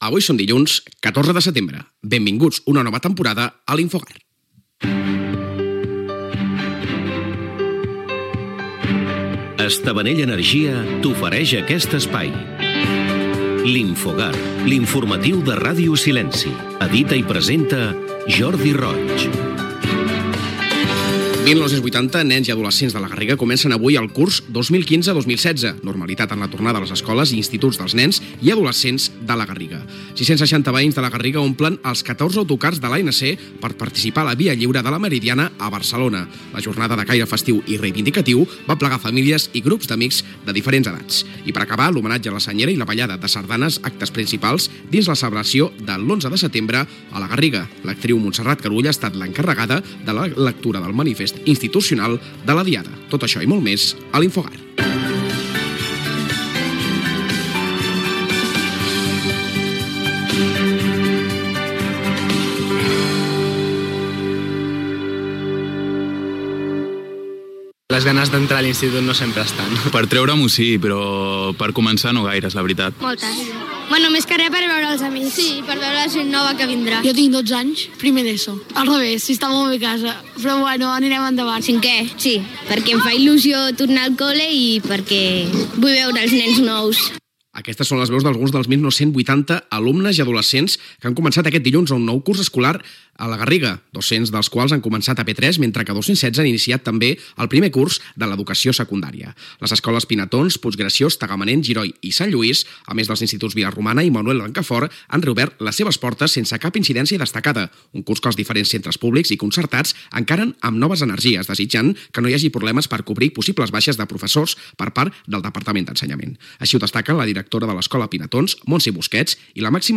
Data, careta del programa, sumari, informació sobre l'inici del curs escolar 2015-2016 a La Garriga.
Informatiu